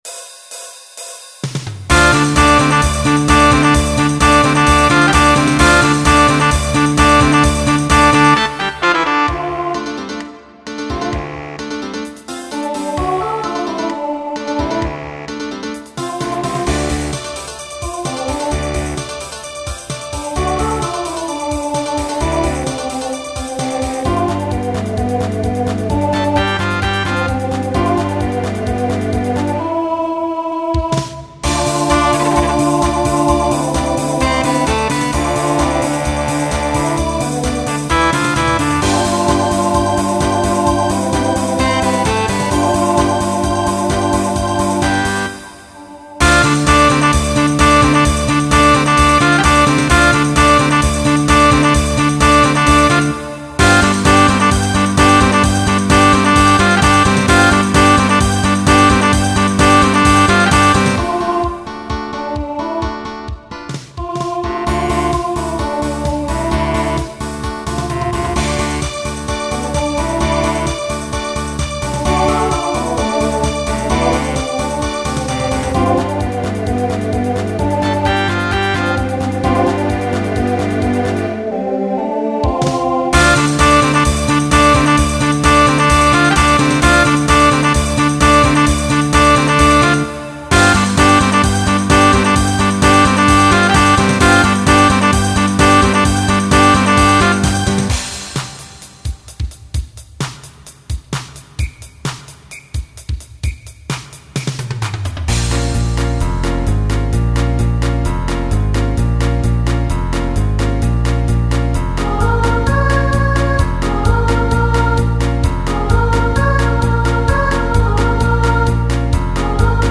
Genre Pop/Dance
Transition Voicing Mixed